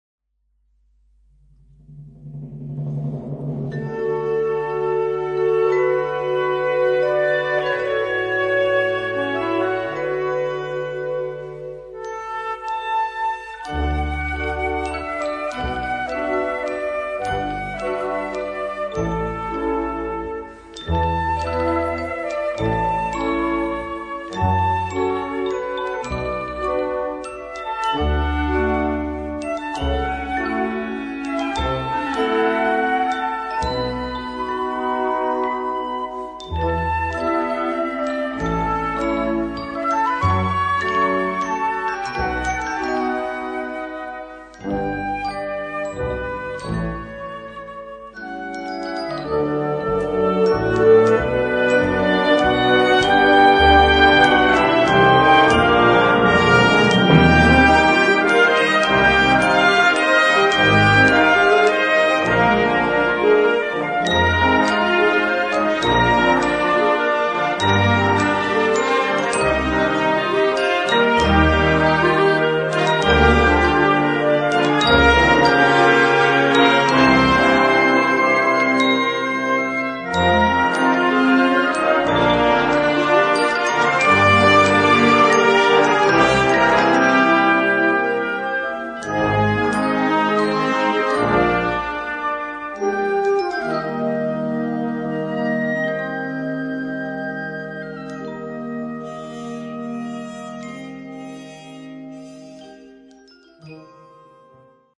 Noten für Blasorchester.